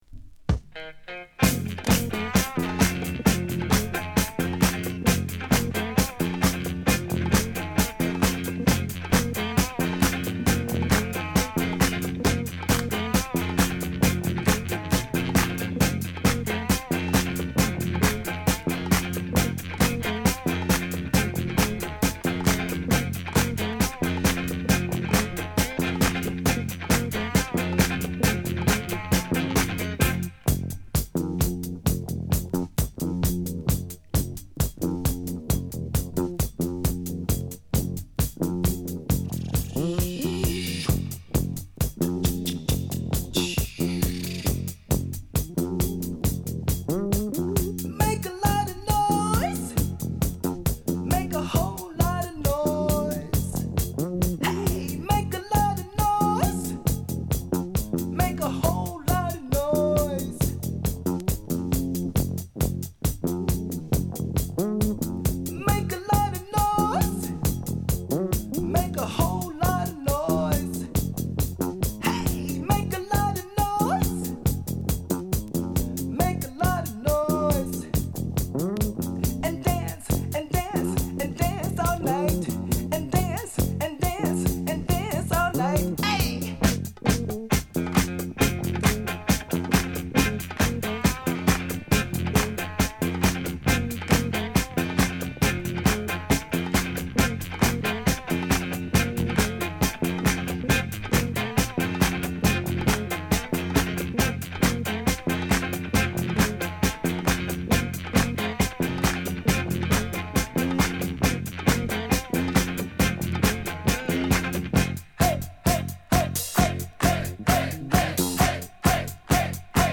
贅肉をそぎ落としたシンプルかつファットなダンストラックを披露！